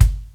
56BRUSHBD -L.wav